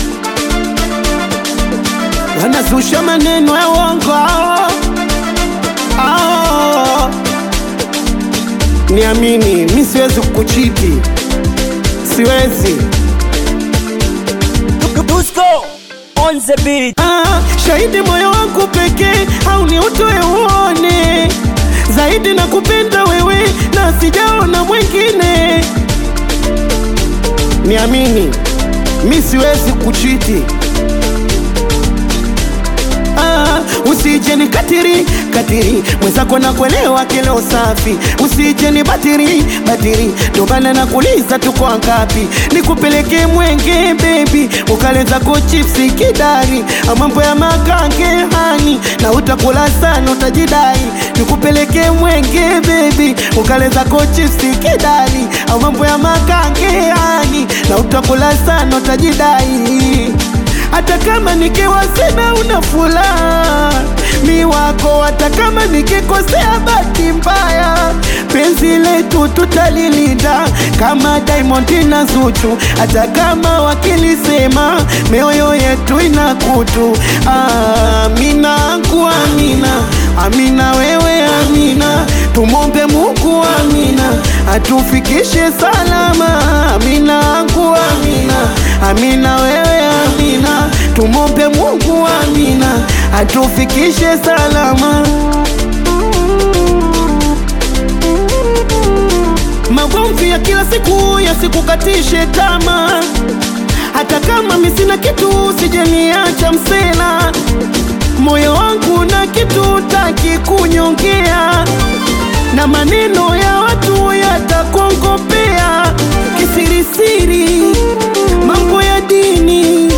singeli anthem